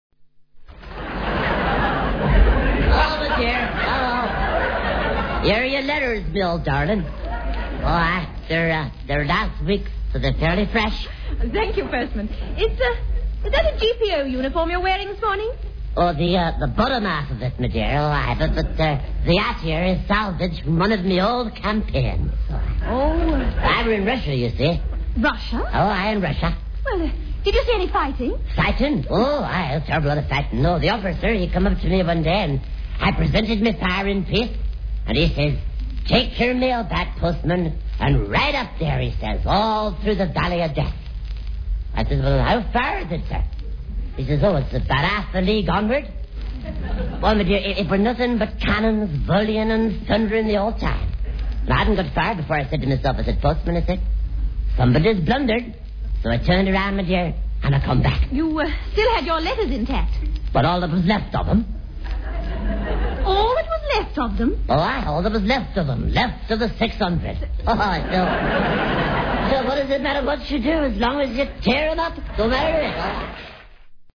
This character spoke no English, but had a peculiar (nonsense) wartime catchphrase, supposedly cod-Norwegian, To the shrill call of his name,  he would reply something like "Harun!".